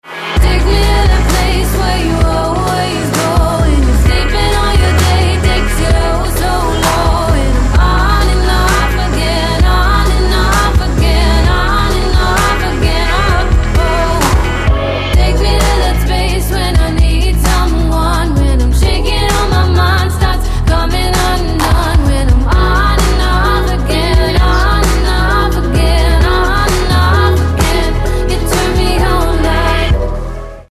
• Качество: 256, Stereo
поп
dance
Electropop
vocal